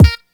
GUnit Synth9.wav